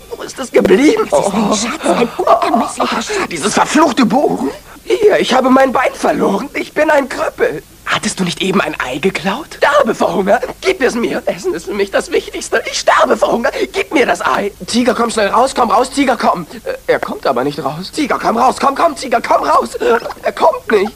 Als ich davon las, recherchierte ich ein wenig und war hellauf begeistert, als ich auf Youtube eine alte Fernseh-Aufzeichnung fand, die dort jemand vor 3 Jahren online stellte.